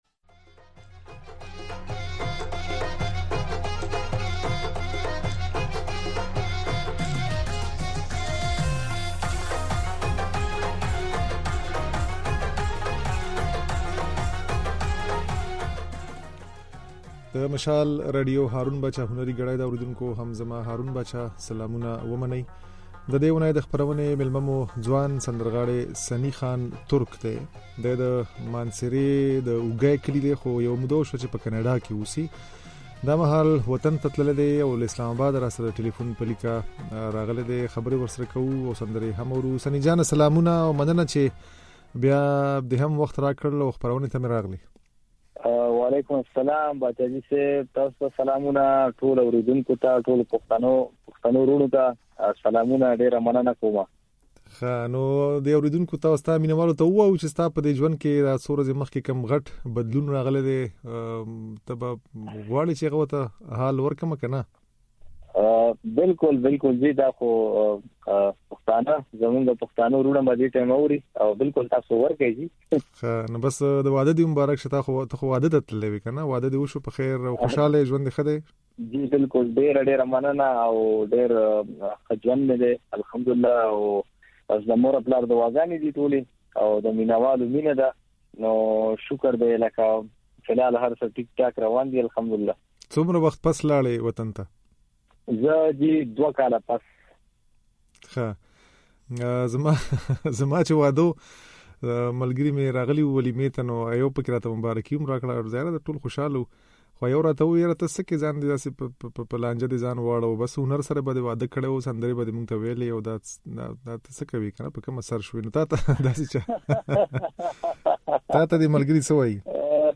او ځينې سندرې يې د غږ په خای کې اورېدای شئ